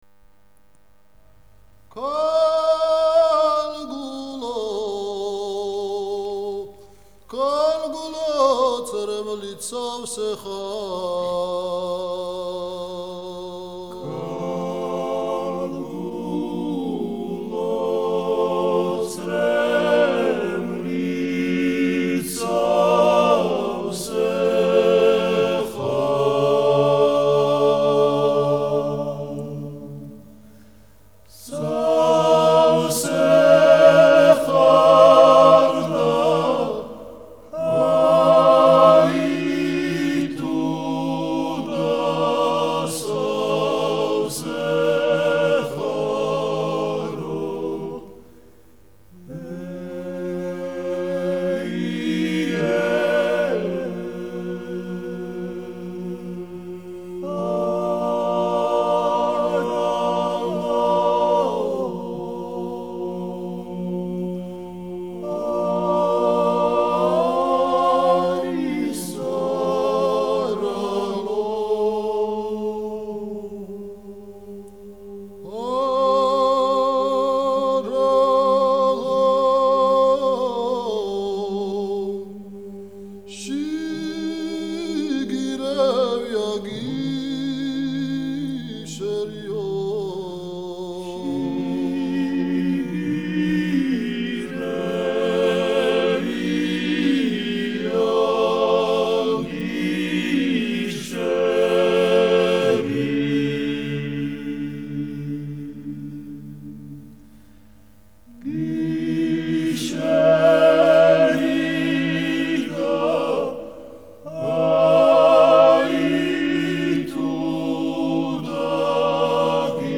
Молдавия: студенческий оркестр.